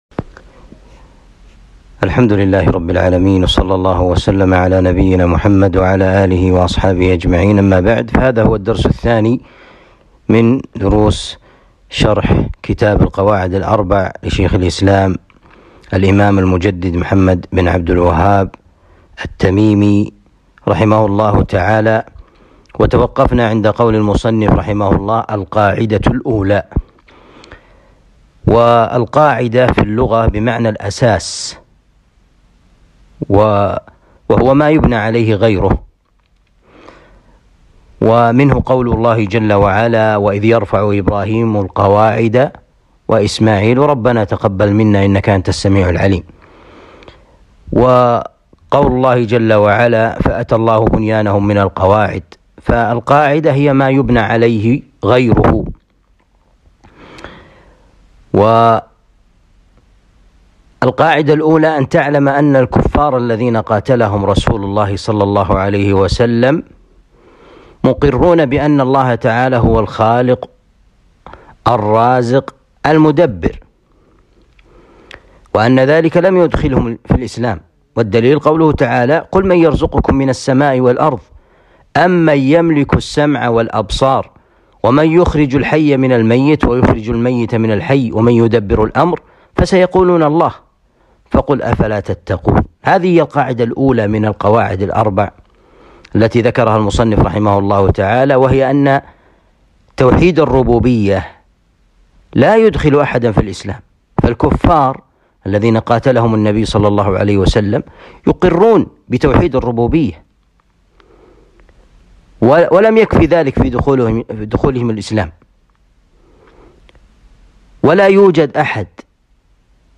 2. الدرس الثاني من شرح القواعد الأربع